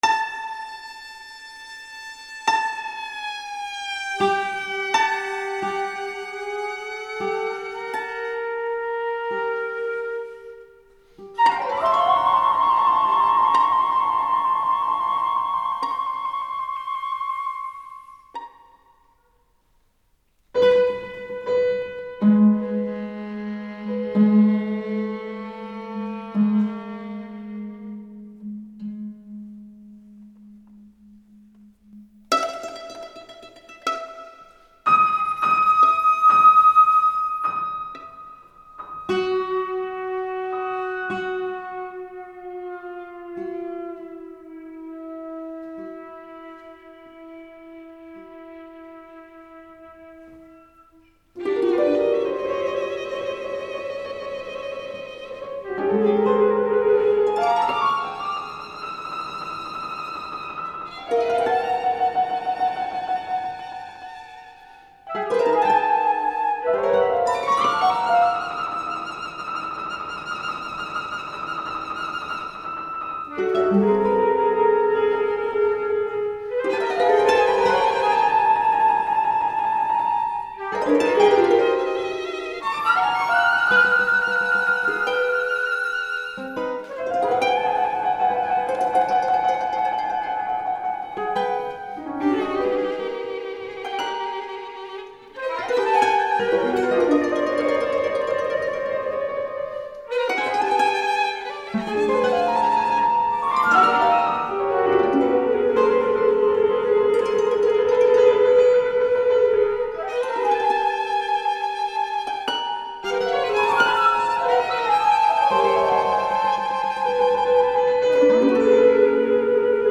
Silence Please para flauta, violín, guitarra, arpa y piano